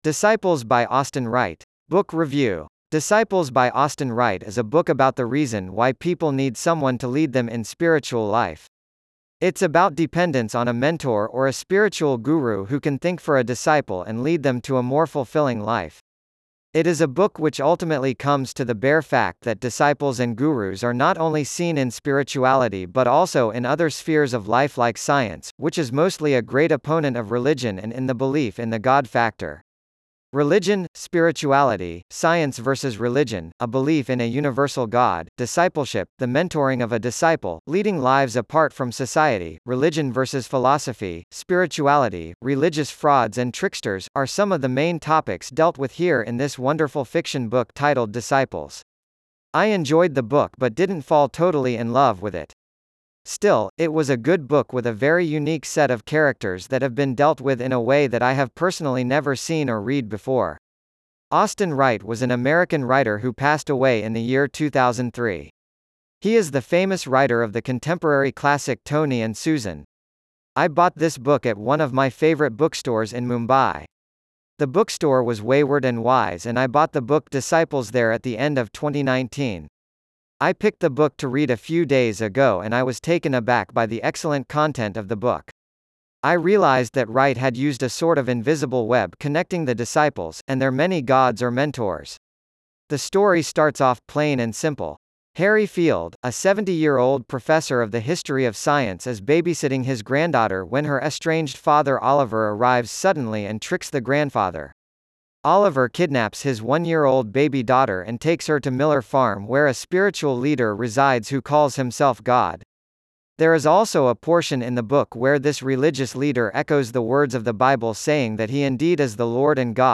Disciples-by-Austin-Wright-Book-Review_en-US-Wavenet-A.wav